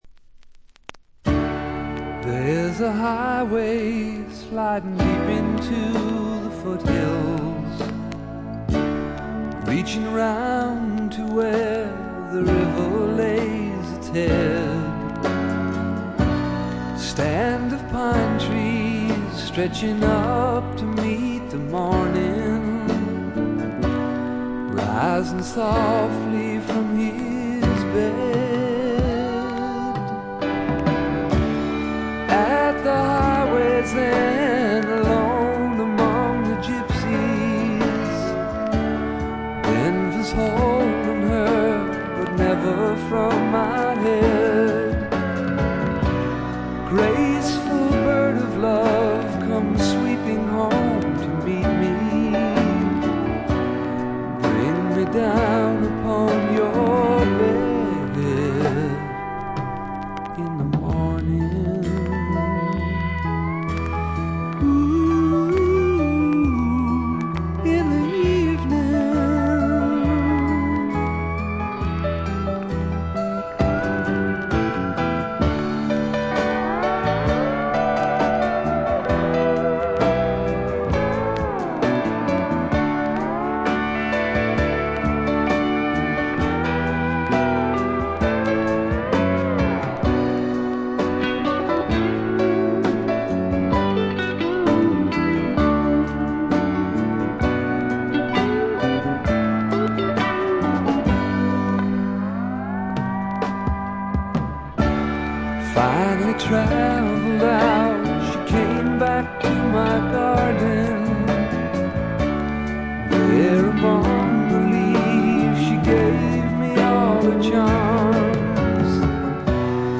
バックグラウンドノイズ、チリプチがやや多め大きめです。
試聴曲は現品からの取り込み音源です。
Vocals, Acoustic Guitar